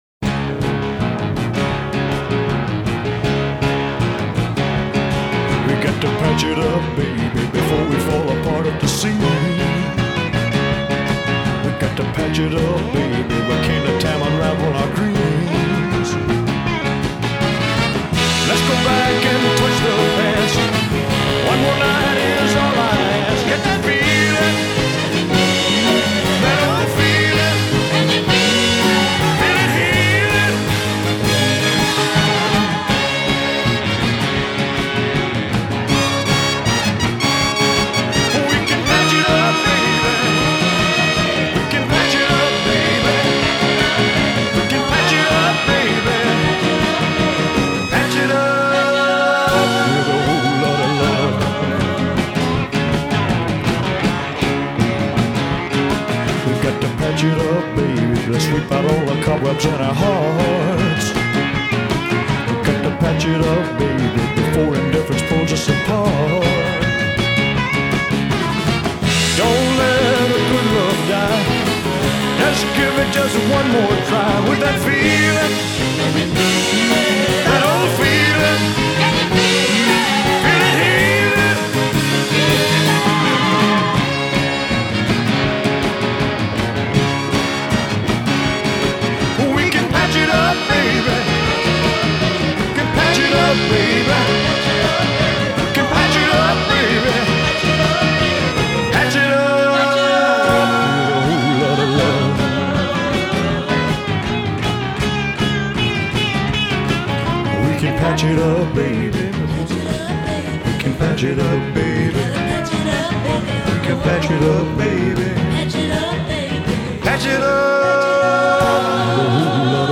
it’s a riot of fuzz bass
larynx-loosening guttural grunt
roof-raisin’ female voices in the background